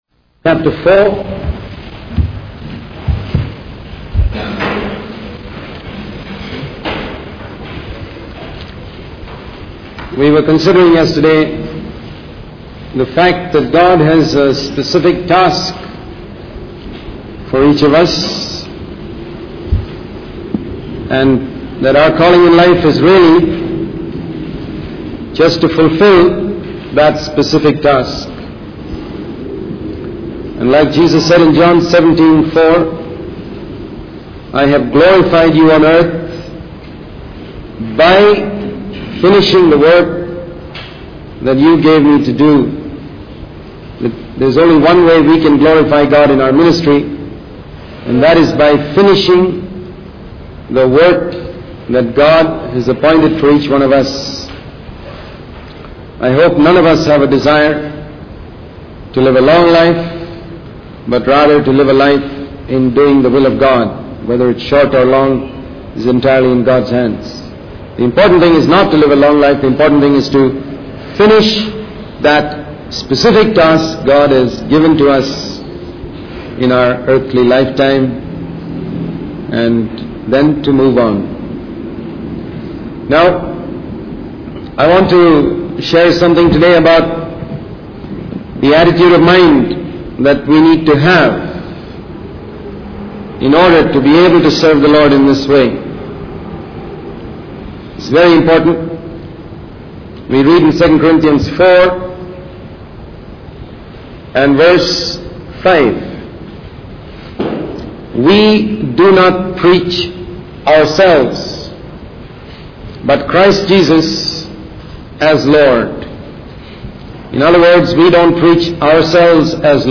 In this sermon, the speaker emphasizes the importance of fulfilling the specific task that God has appointed for each individual.